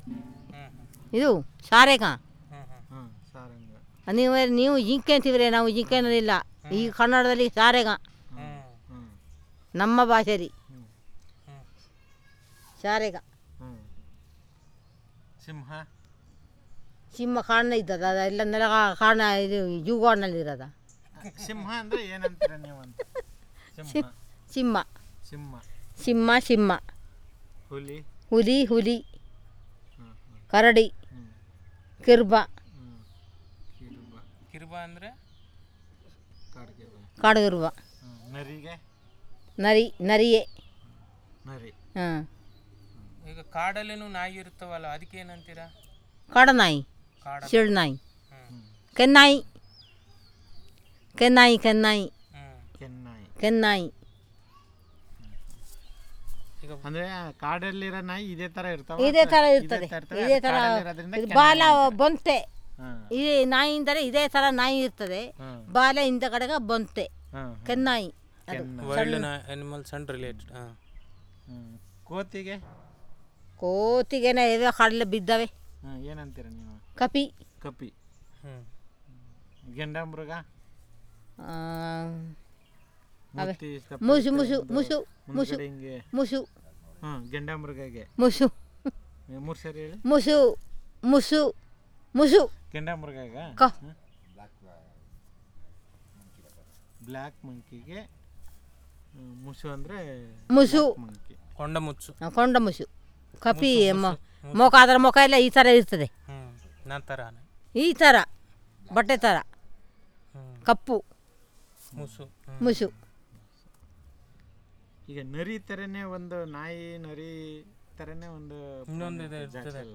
Elicitation of words about Wild Animals and related